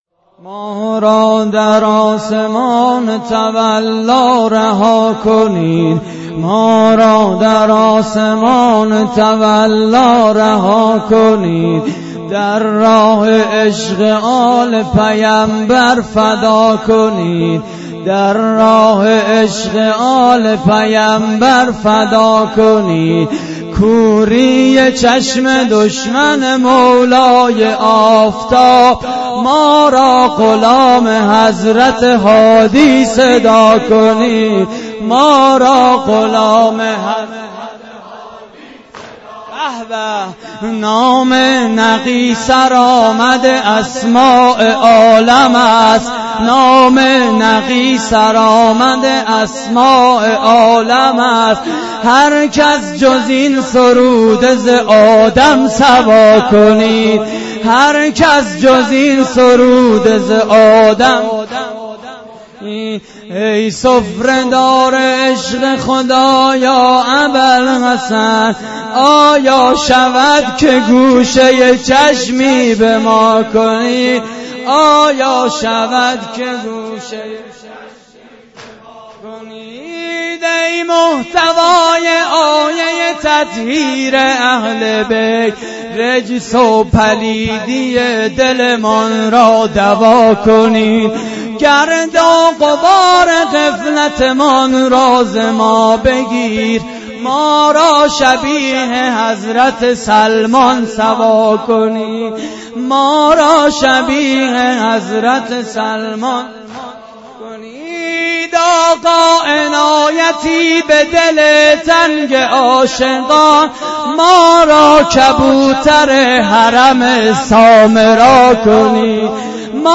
واحد: ما را غلام حضرت هادی صدا کنید
مراسم عزاداری شهادت امام هادی (ع)
مسجد لولاگر